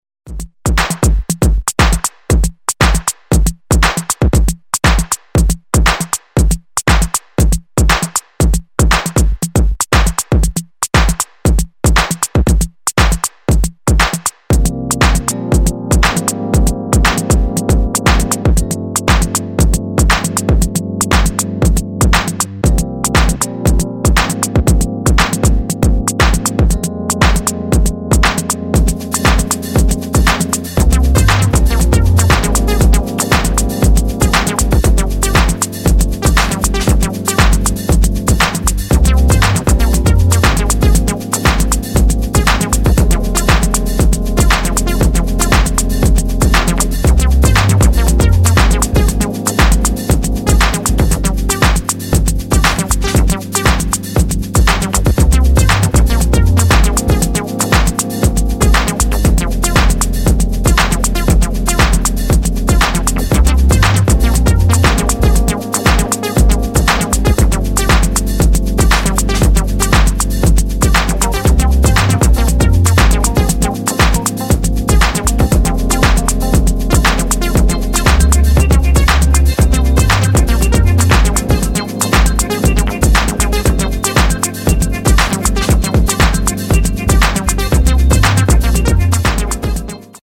[ TECH HOUSE / BASS ]